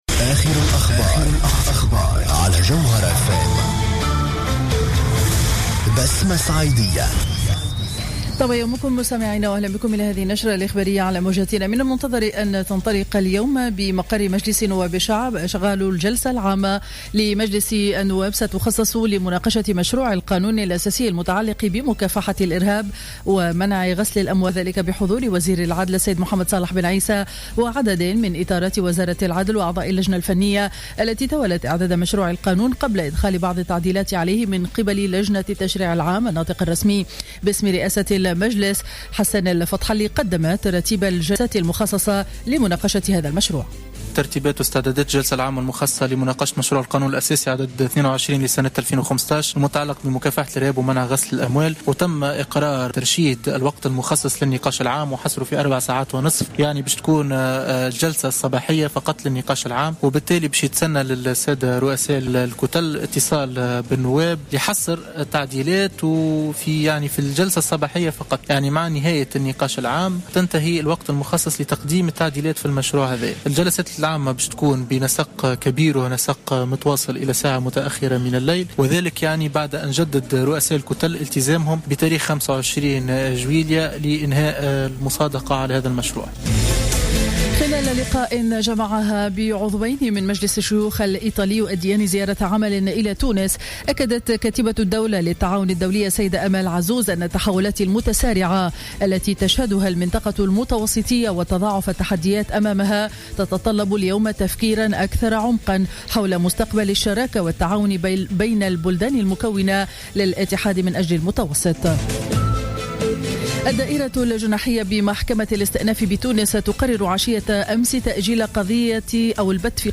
نشرة أخبار السابعة صباحا ليوم الاربعاء 22 جويلية 2015